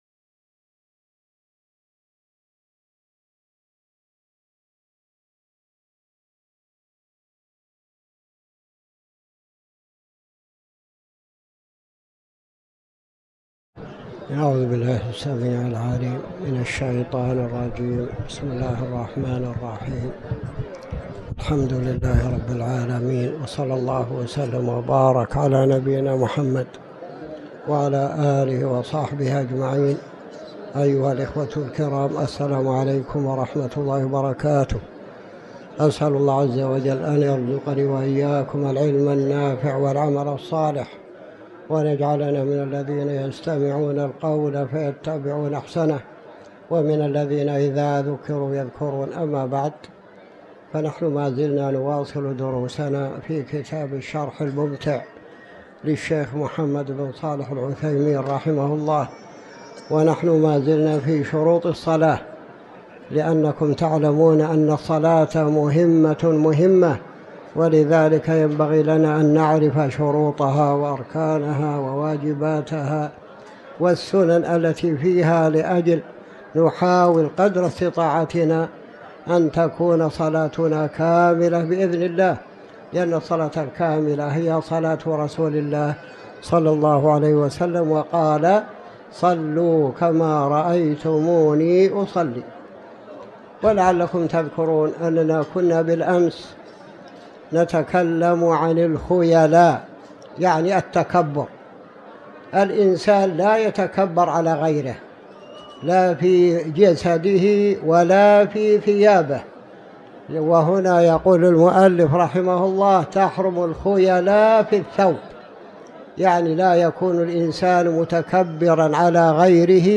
تاريخ النشر ١٢ جمادى الآخرة ١٤٤٠ هـ المكان: المسجد الحرام الشيخ